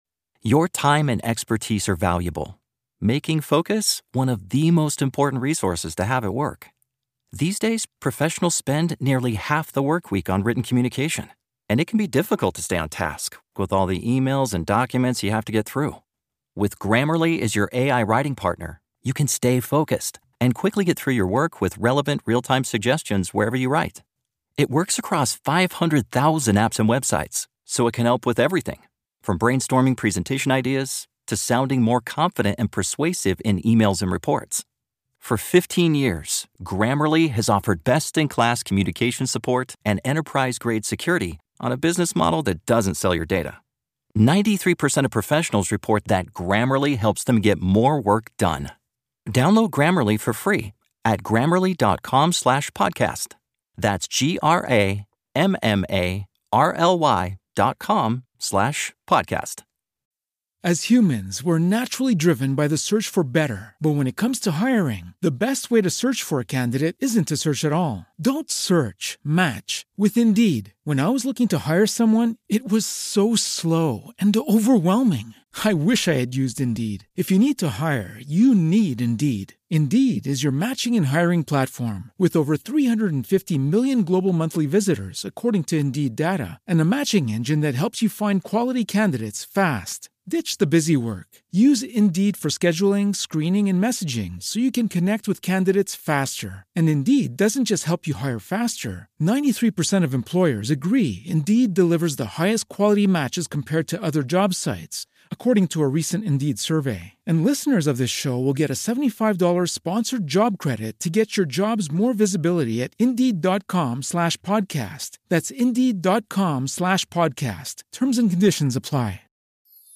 13. Türchen | Im Fahrenden Ritter - Eberkopf Adventskalender ~ Geschichten aus dem Eberkopf - Ein Harry Potter Hörspiel-Podcast Podcast